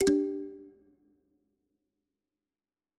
power-unplug.wav